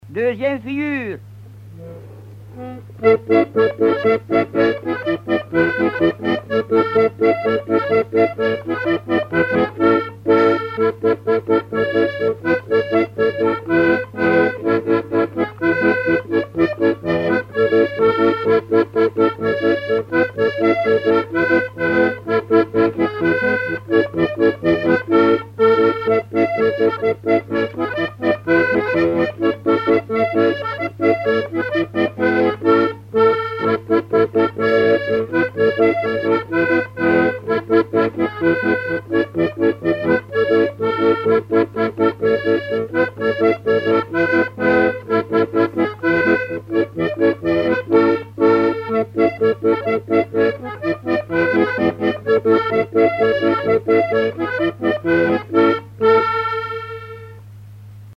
danse : quadrille
Pièce musicale inédite